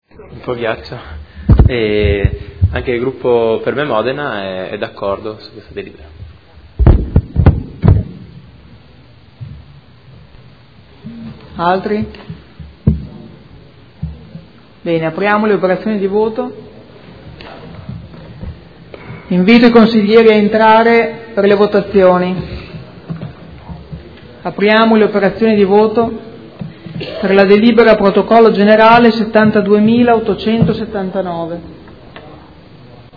Seduta dell'11 giugno.
Dichiarazioni di voto